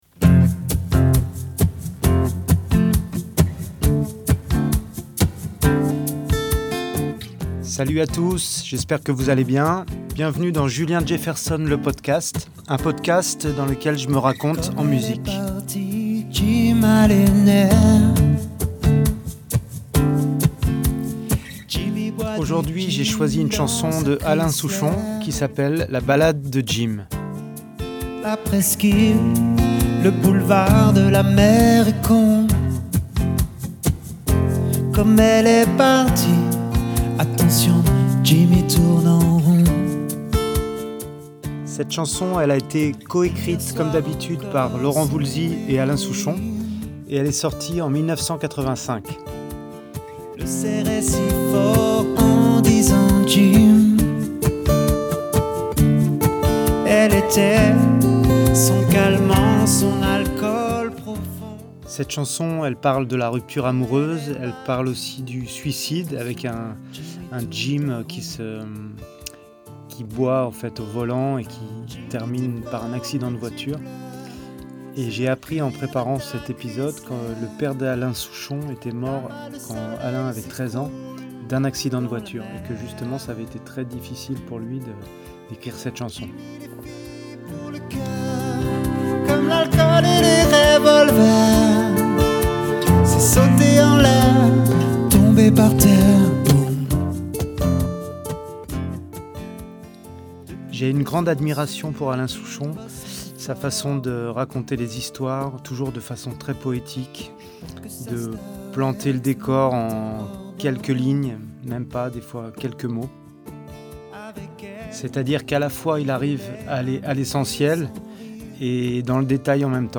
et nous en livre une version acoustique ei intimiste.